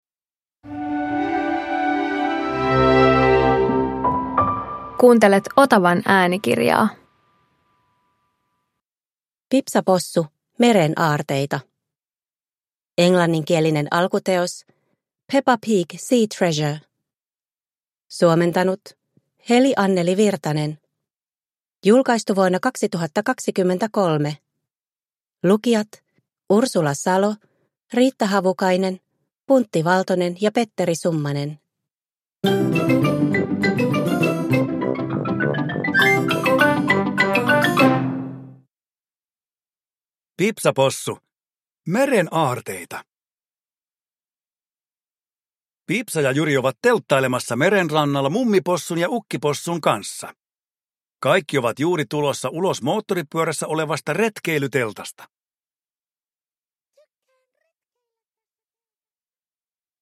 Pipsa Possu - Meren aarteita – Ljudbok